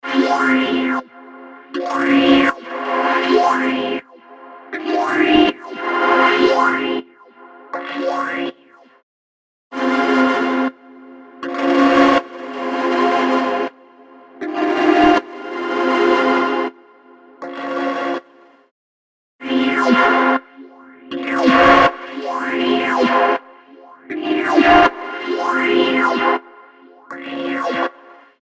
デモ・サウンド
Instant Phaser Mk II – Eventide – Synth Pad – Wax Paper
Instant Phaser Mk II - Eventide - Synth Pad - Wax Paper.mp3